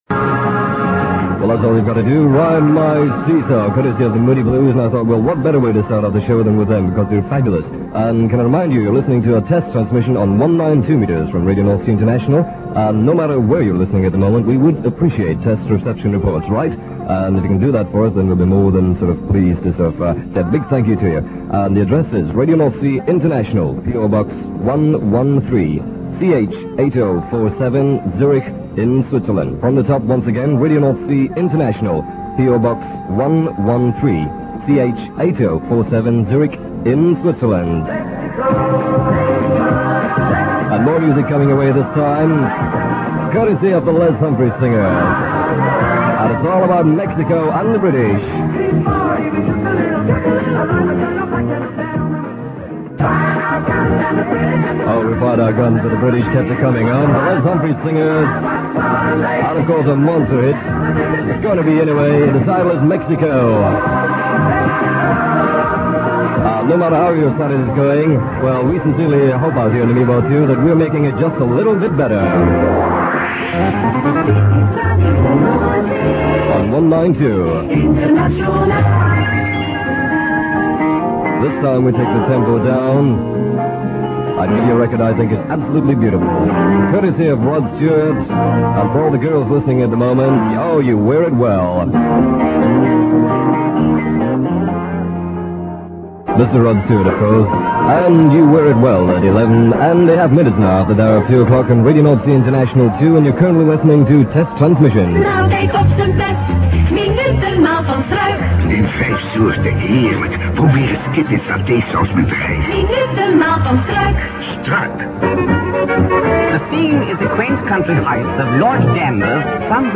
There was noticeable audio breakthrough from the Dutch service whose programmes could be heard clearly in the background.